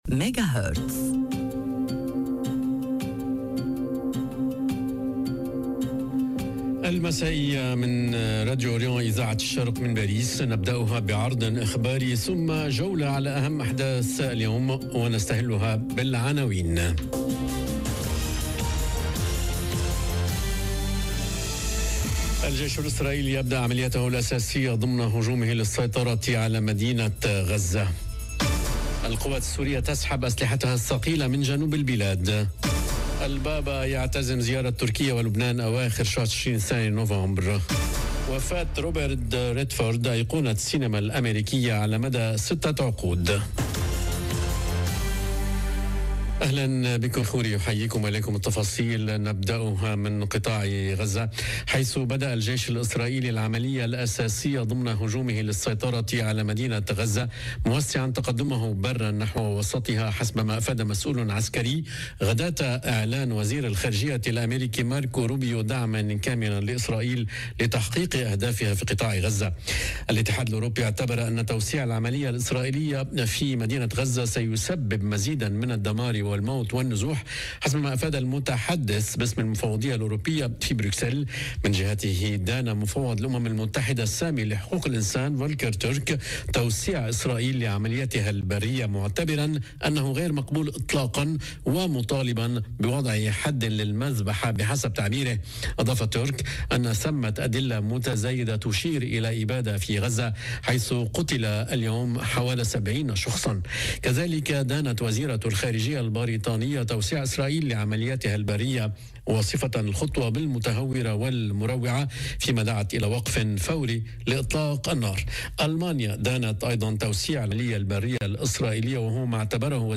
Magazine d'information du 16/09/2025